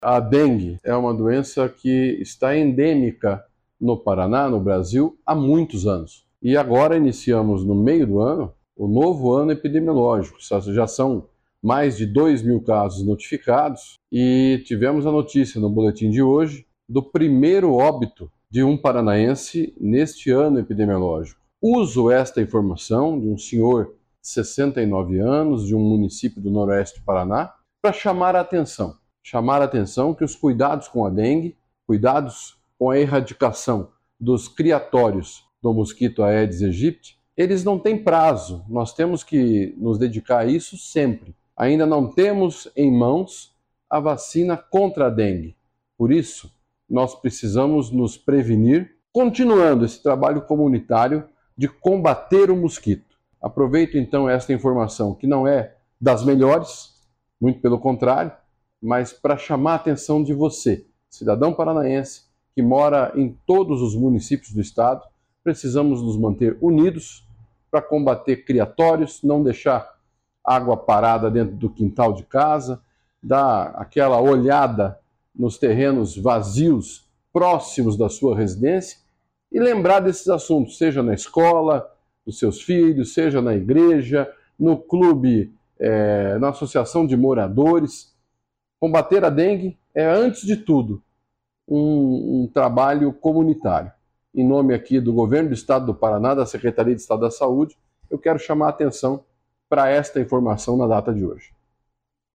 Sonora do secretário da Saúde, Beto Preto, sobre o informe da dengue que confirma a primeira morte neste período epidemiológico